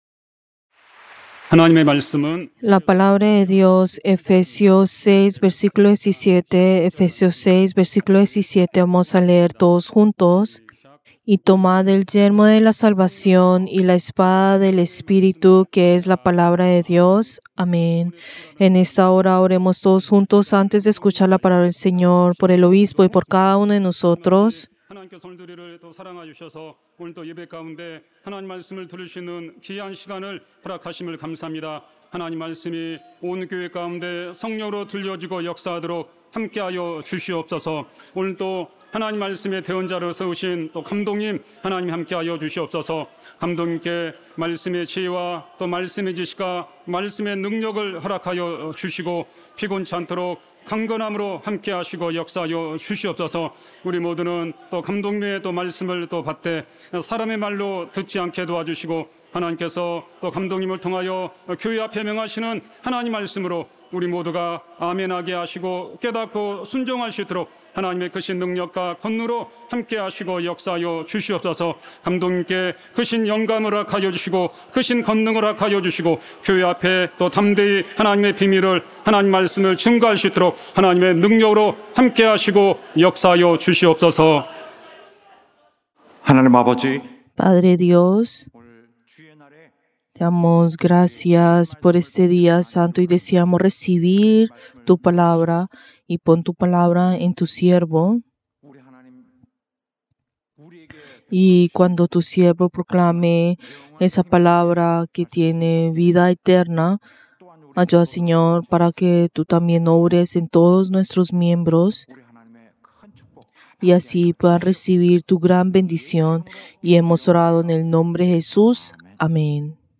Servicio del Día del Señor del 27 de julio del 2025